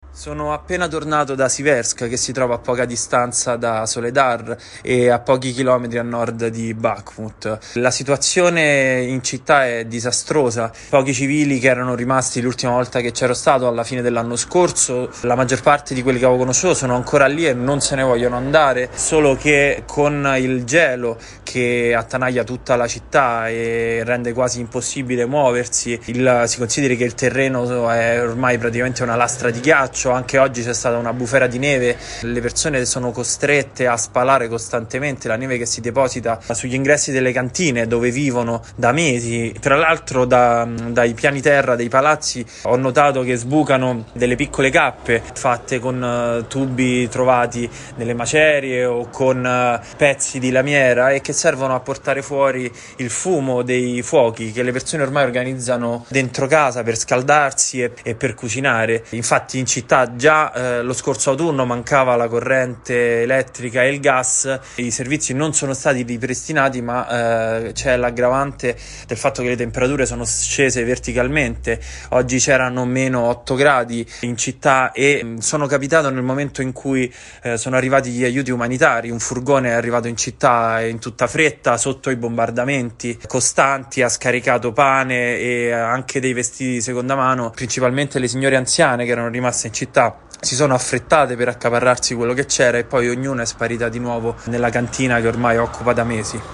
Nel paese aggredito si continua a temere l’offensiva russa, che potrebbe portare a una nuova recrudescenza del conflitto. Poco fa abbiamo raggiunto a Siversk, nella regione di Donetsk,